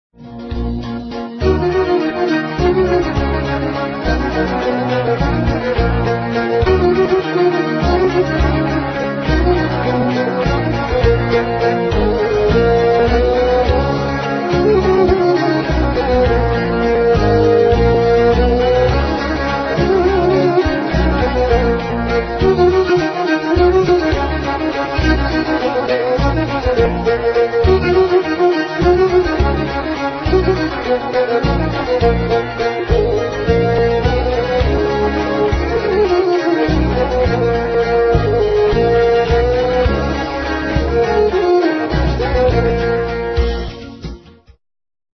Double Omal. This dance is sometimes mixed with Dipat so that while the dancers keep doing the same dance the melody shifts half a bar forward and back